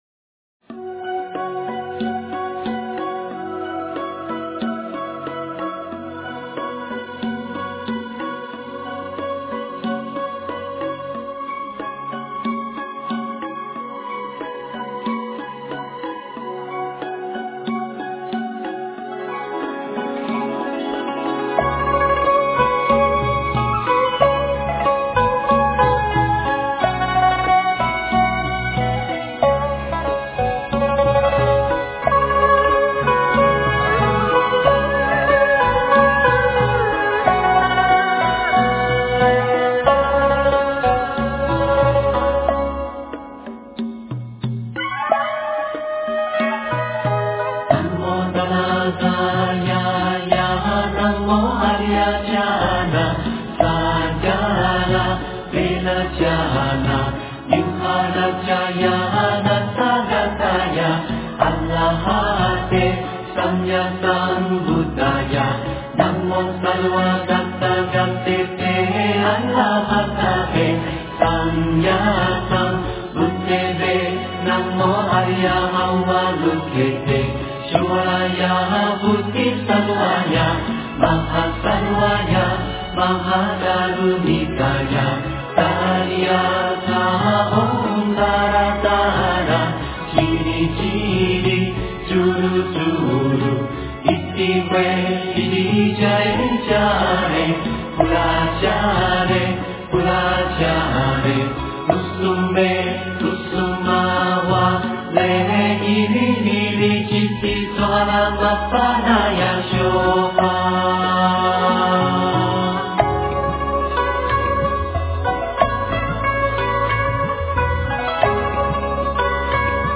佛音 诵经 佛教音乐 返回列表 上一篇： 心经(粤语